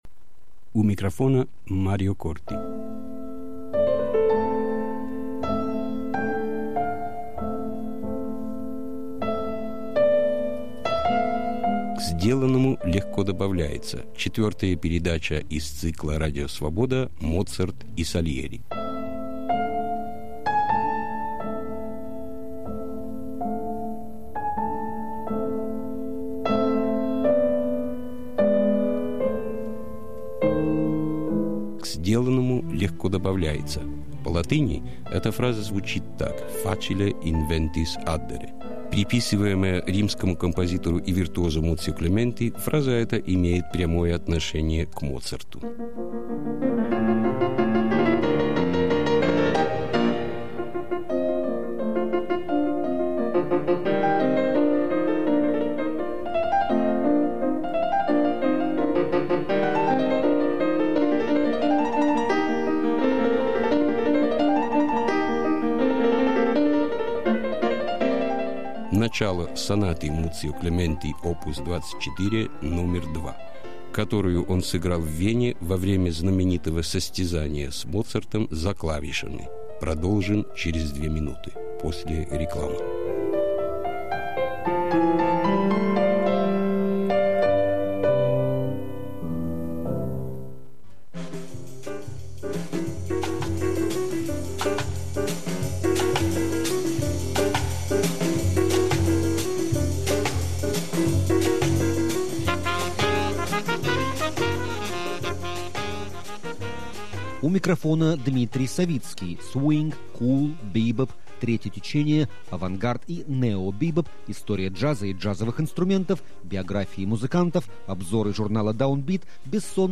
Моцарт и Сальери. Историко-музыкальный цикл из девяти передач, 1997 год.